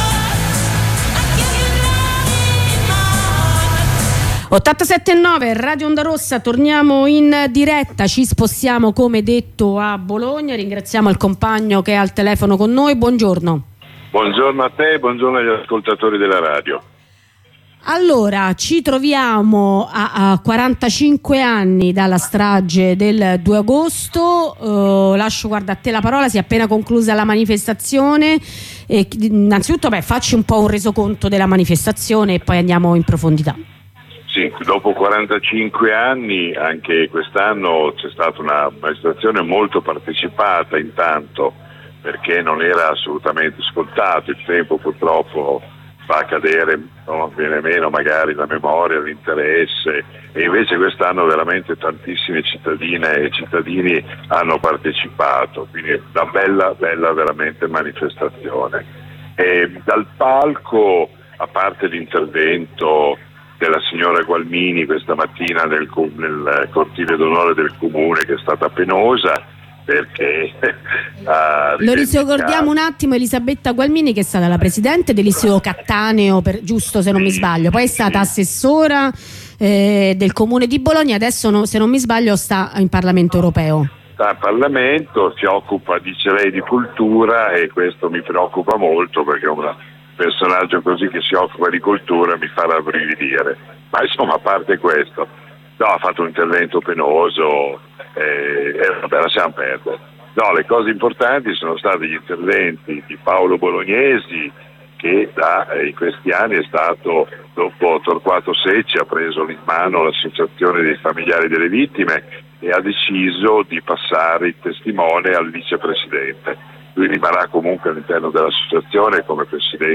Ne parliamo con un compagno bolognese